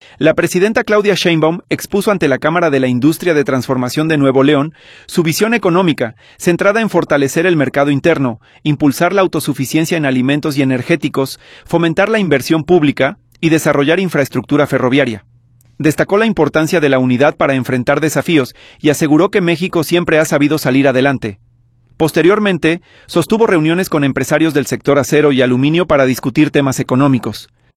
La presidenta Claudia Sheinbaum expuso ante la Cámara de la Industria de Transformación de Nuevo León (Caintra) su visión económica, centrada en fortalecer el mercado interno, impulsar la autosuficiencia en alimentos y energéticos, fomentar la inversión pública y desarrollar infraestructura ferroviaria. Destacó la importancia de la unidad para enfrentar desafíos y aseguró que México siempre ha sabido salir adelante.